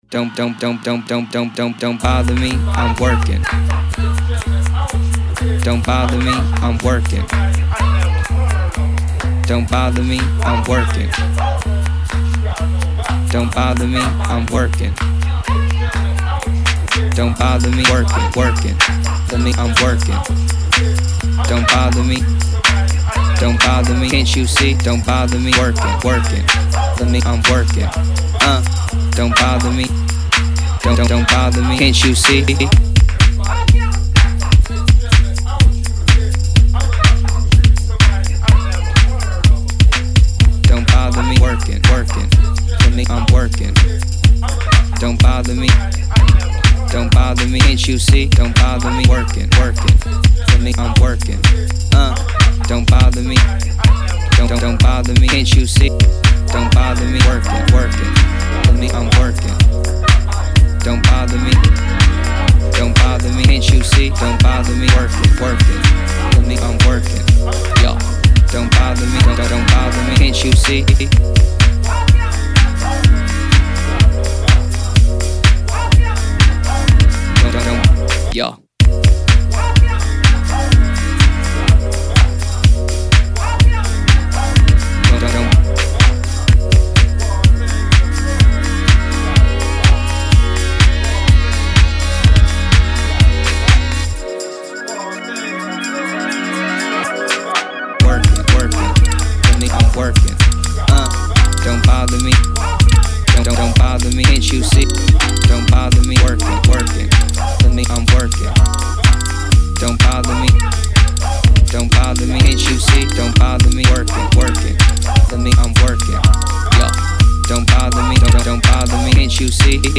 Holed up in his home studio